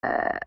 body-noises
burp1.wav